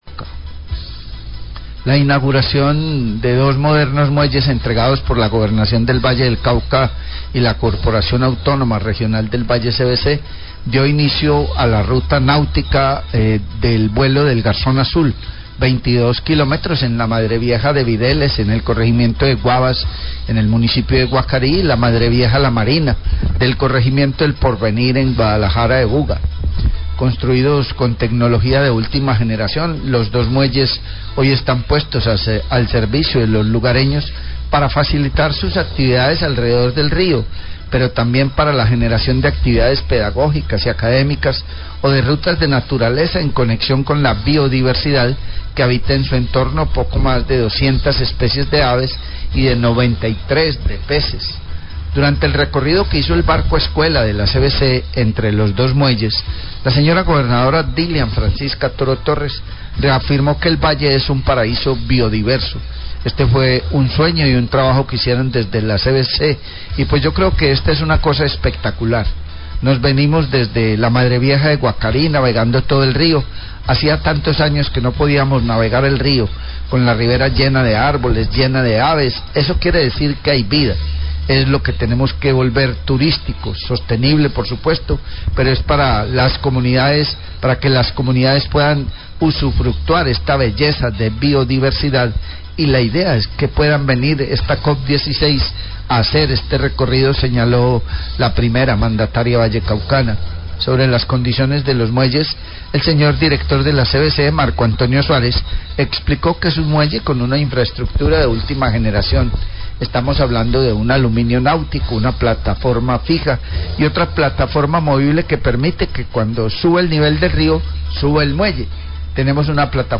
Gobernación del Valle y CVC entregan ruta nautica 'El Vuelo del Garzón Azúl' en la COP16, la entrega de los dos muelles de última tecnología para Guacarí y Guadalajara de Buga. La Gobernadora del Valle Del Cauca habla al respecto.